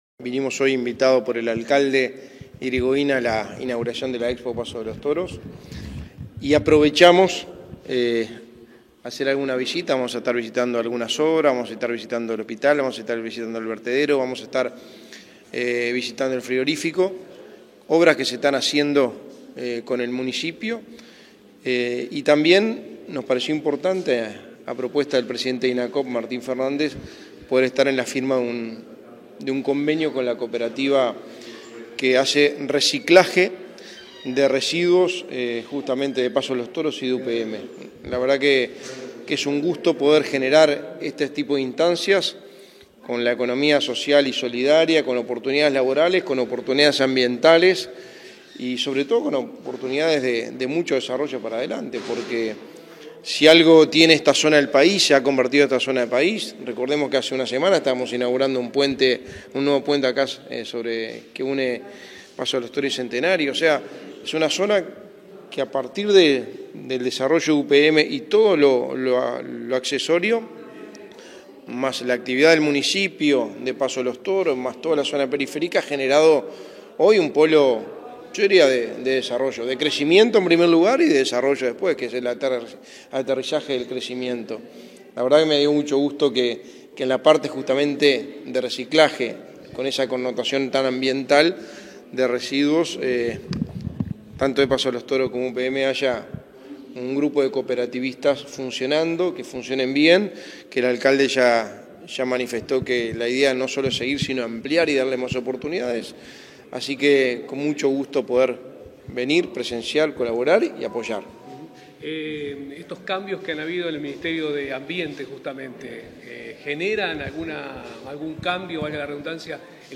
Declaraciones del secretario de la Presidencia, Álvaro Delgado, tras firma de convenio
Declaraciones del secretario de la Presidencia, Álvaro Delgado, tras firma de convenio 10/02/2023 Compartir Facebook X Copiar enlace WhatsApp LinkedIn Tras participar en la firma de un convenio en Paso de los Toros, este 9 de febrero, el secretario de la Presidencia, Álvaro Delgado, realizó declaraciones a la prensa.